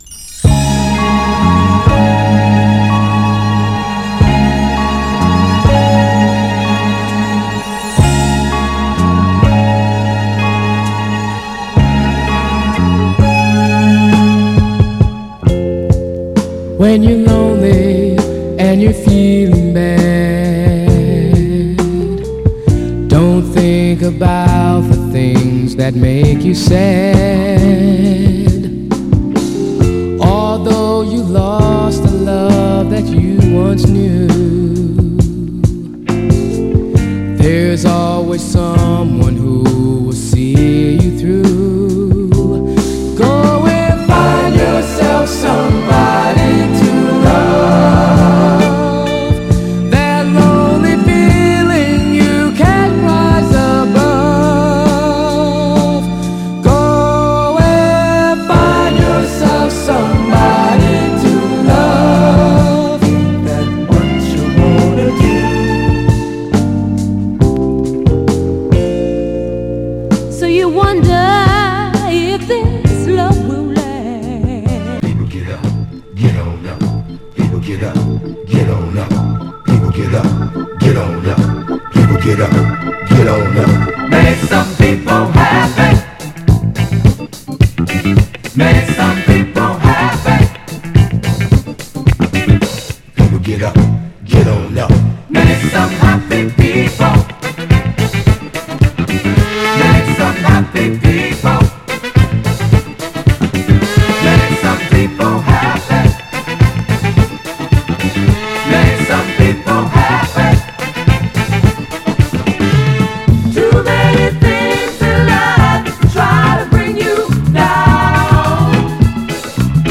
特に後半のキズ部分で暫くノイズ出ます。
FORMAT 7"
※試聴音源は実際にお送りする商品から録音したものです※